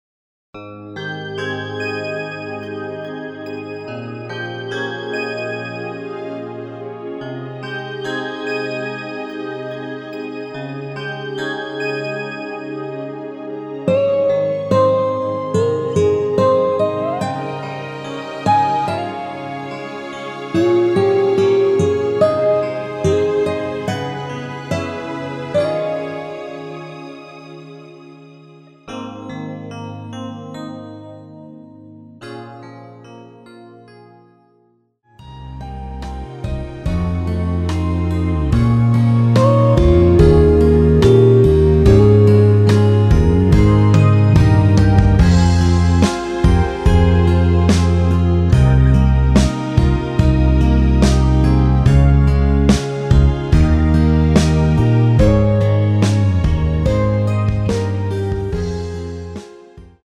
대부분의 여성분이 부르실수 있는 키로 제작 하였습니다.
Ab
앞부분30초, 뒷부분30초씩 편집해서 올려 드리고 있습니다.
중간에 음이 끈어지고 다시 나오는 이유는